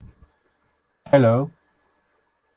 speech-8kHz-test.wav